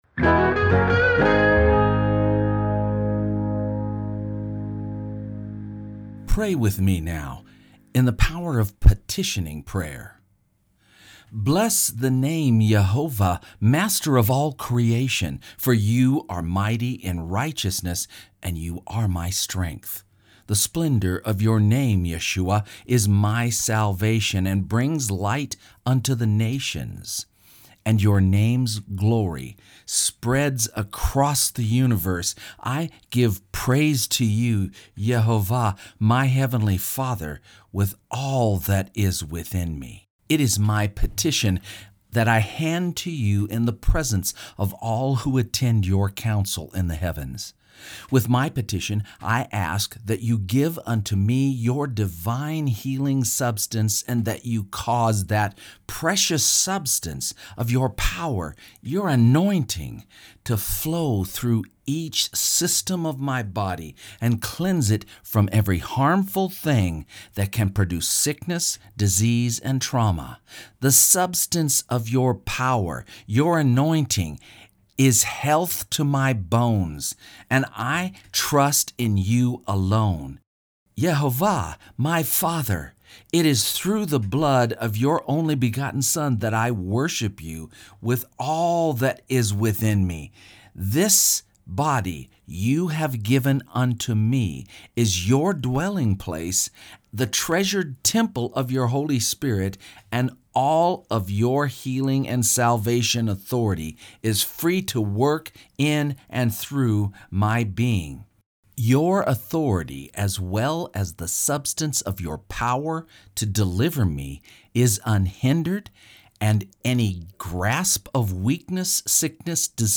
This is a recorded prayer, for tomorrow's Shabbat Shalom program: